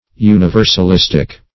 Universalistic \U`ni*ver`sal*is"tic\, a.